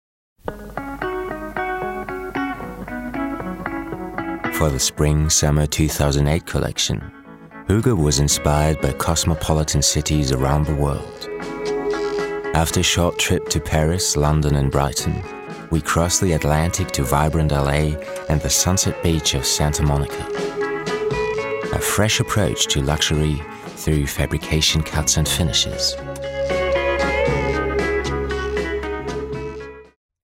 Sehr angenehme, tiefe, warme Stimme, äußerst variabel.
Mein Englisch ist sehr neutral (näher am Britischen als am Amerikanischen) und wird gerne für internationale Produktionenen eingesetzt.
Sprechprobe: Werbung (Muttersprache):
English voice over artist with recording studio